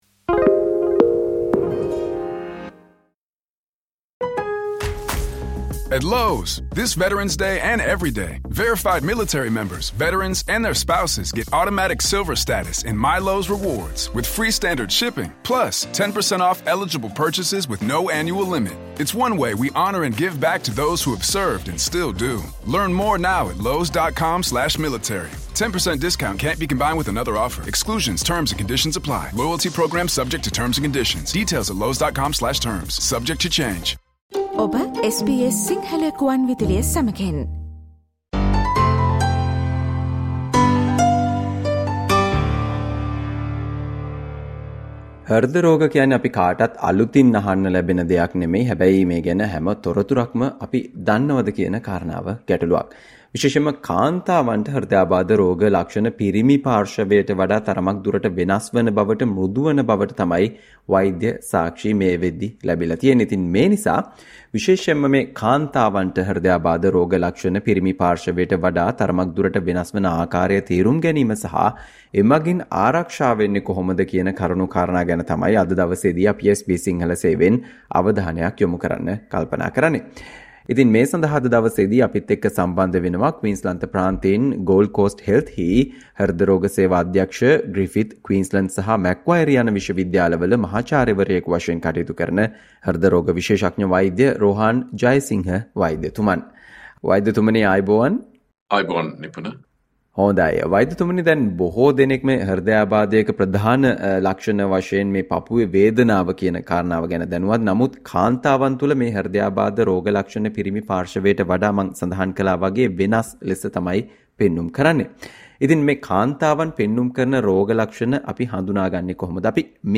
කාන්තාවන් හට හෘදයාබාධ රෝග ලක්ෂණ පිරිමි පාර්ශවයට වඩා තරමක් දුරට වෙනස් හේතුවෙන් කාන්තාවන් මුහුණ දෙන අවධානම පිළිබඳව SBS සිංහල සේවය සිදු කල සාකච්ඡාවට සවන් දෙන්න